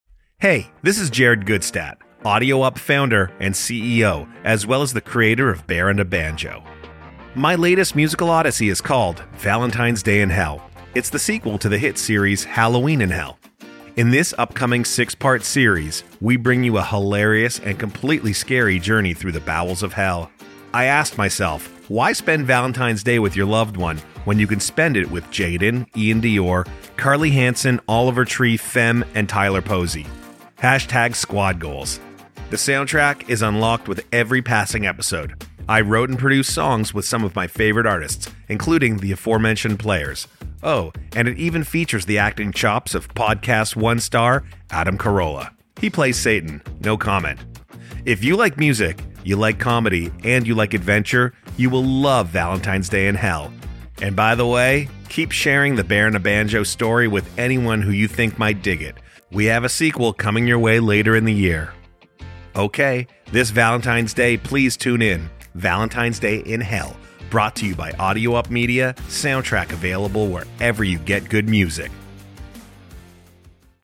Bear and a Banjo is a new musical podcast that tells the incredibly tall tale of a band that time forgot. Although history may have left them behind, Bear and Banjo rubbed shoulders with some of the greatest musical icons in history—from Leadbelly and Sister Rosetta Tharpe to Little Richard and Bob Dylan— unwittingly influencing the course of 20th century Americana. All their adventures are meticulously archived and presented by Dr. Q, played by Dennis Quaid.